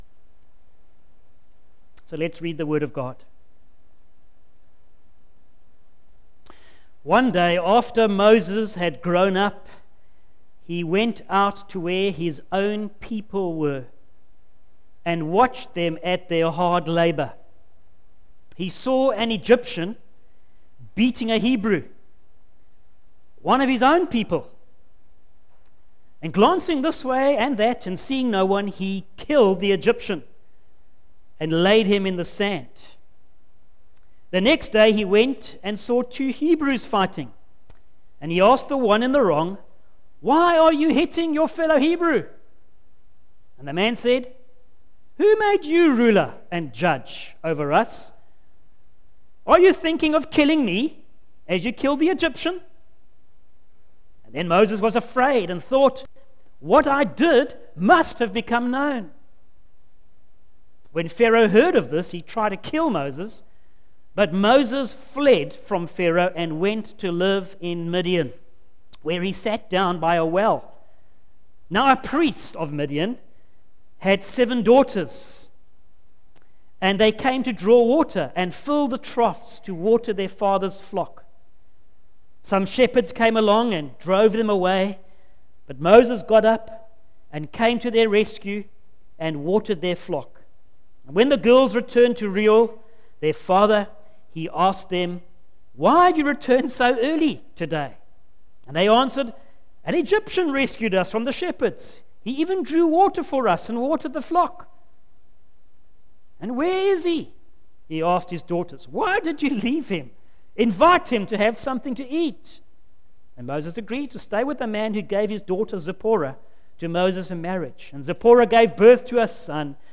Facebook Twitter email Posted in Morning Service